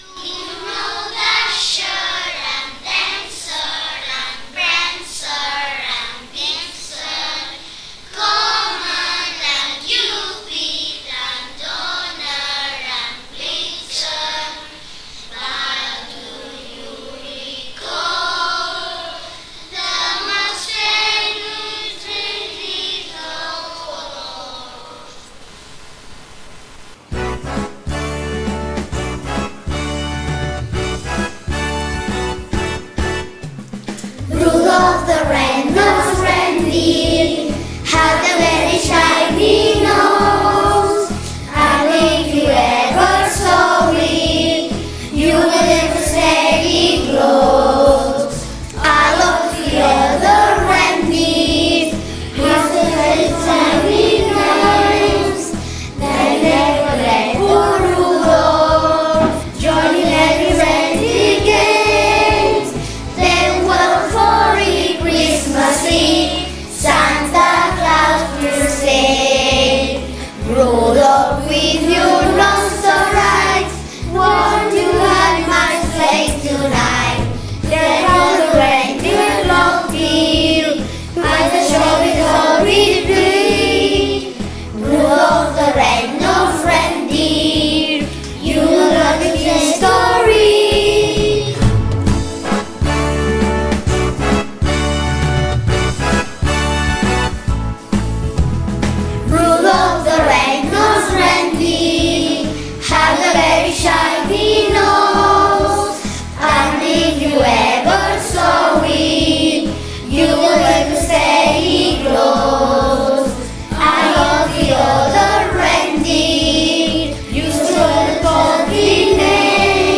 Els nens i nenes de 2n i 3r van cantar la cançó d’en RUDOLF THE RED-NOSED